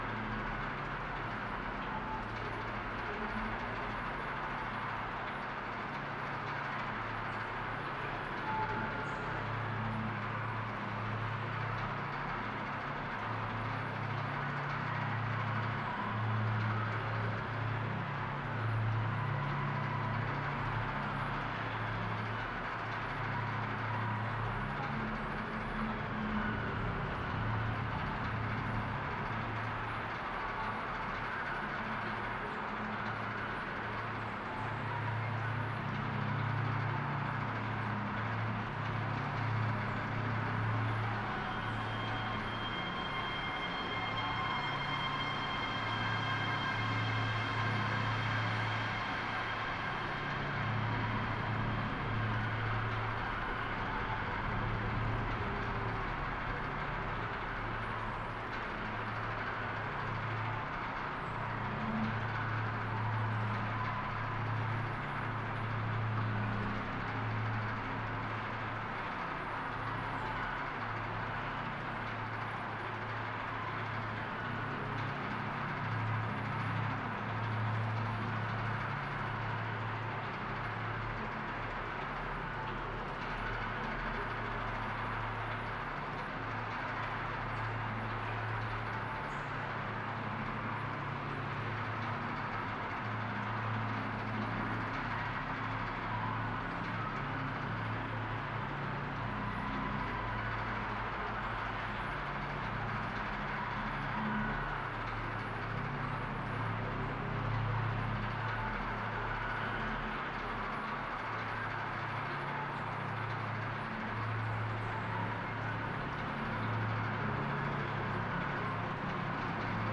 CreepyFactory.ogg